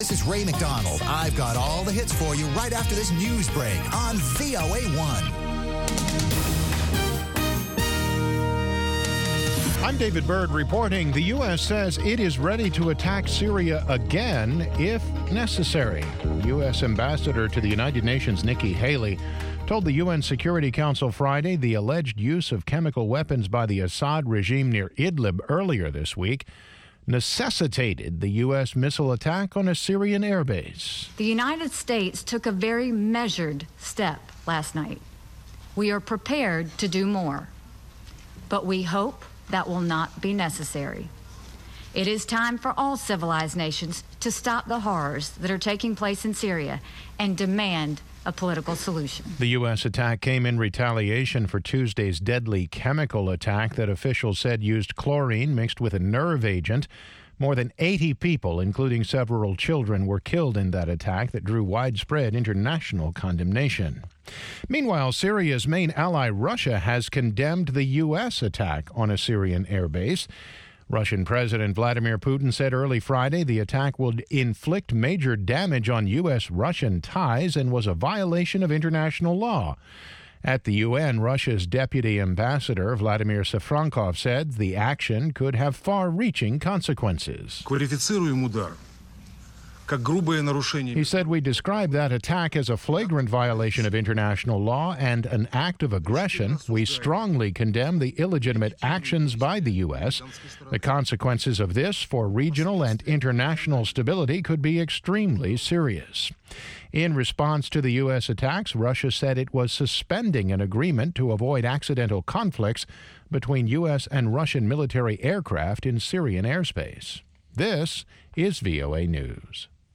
Una discusión de 30 minutos sobre los temas noticiosos de la semana con diplomáticos, funcionarios de gobiernos y expertos.